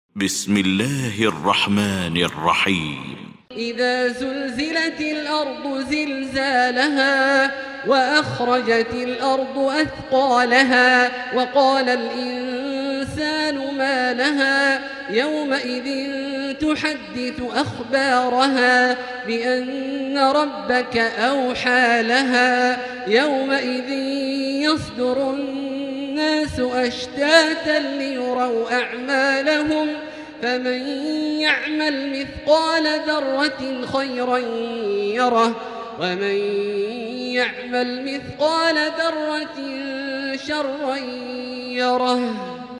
المكان: المسجد الحرام الشيخ: فضيلة الشيخ عبدالله الجهني فضيلة الشيخ عبدالله الجهني الزلزلة